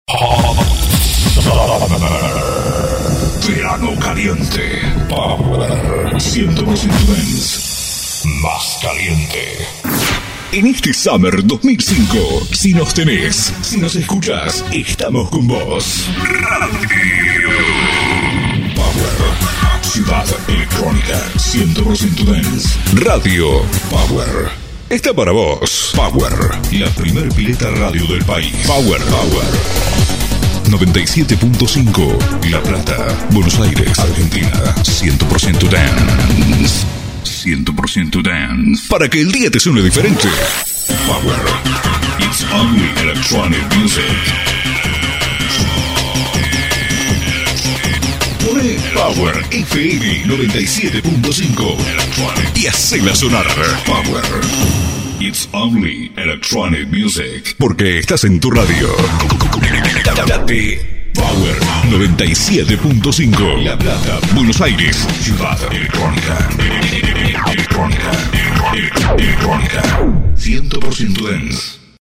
voice over
Demo-arte-energia.mp3